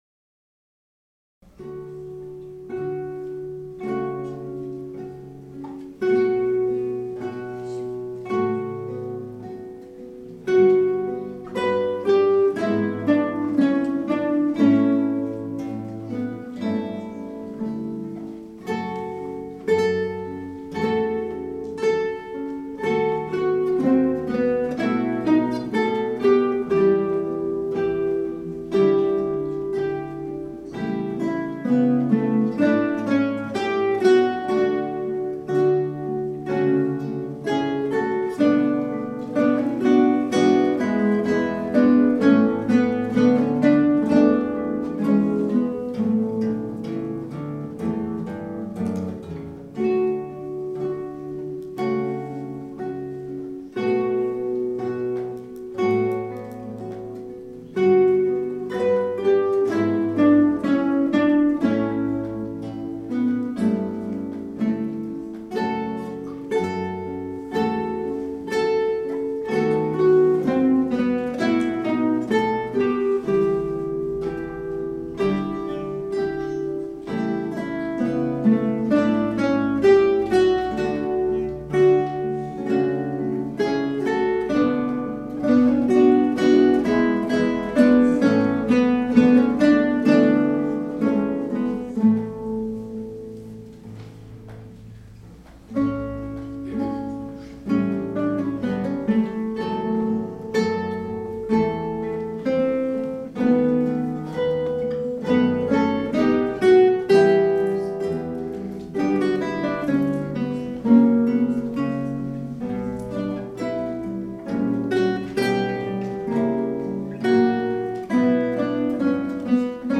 • Kytarový orchestr
Hráči používají klasické kytary ve standardním ladění, 1/8 kytary se sopránovými oktávovými strunami a klasickou basovou kytaru se strunami laděnými o oktávu níže, popř. sólovou elektrickou kytaru (u úprav rockových skladeb). Do některých skladeb bývají také zapojeny perkusní či Orffovy nástroje.